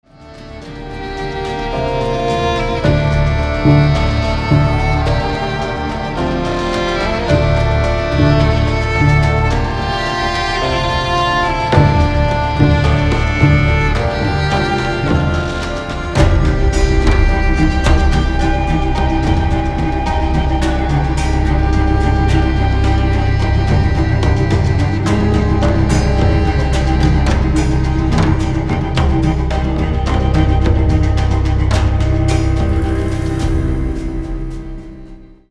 • Качество: 256, Stereo
красивые
мелодичные
инструментальные
из игр
пиратские мотивы